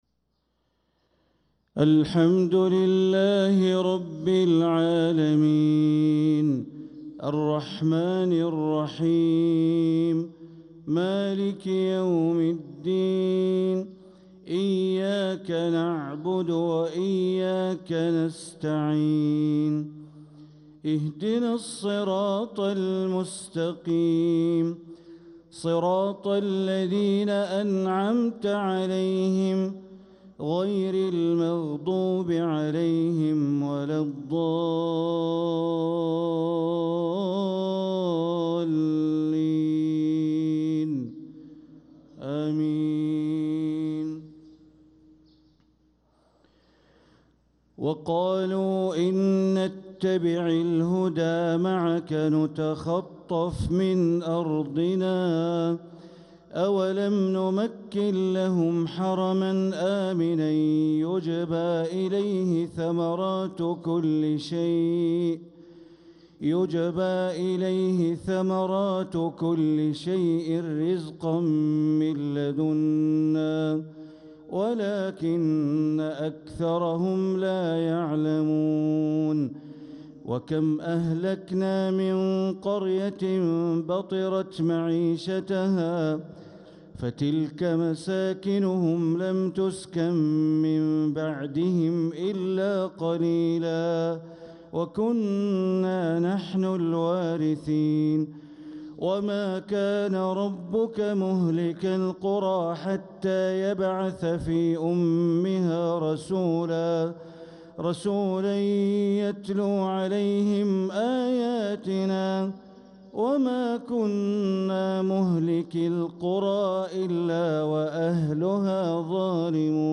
صلاة الفجر للقارئ بندر بليلة 23 ربيع الآخر 1446 هـ
تِلَاوَات الْحَرَمَيْن .